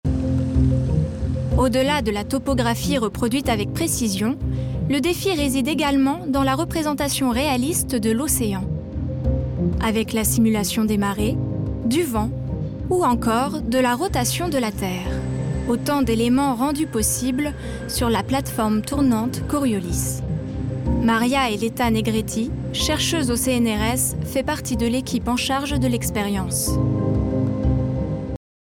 Démo voix off reportage